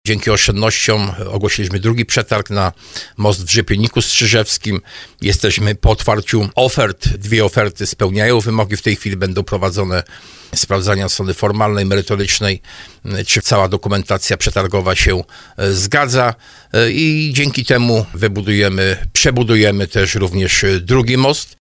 Inwestycja może dojść do skutku, między innymi dzięki zaoszczędzonym pieniądzom na odbudowie mostu w Czermnej, który teraz jest w realizacji – mówił w programie Słowo za Słowo starosta tarnowski Jacek Hudyma.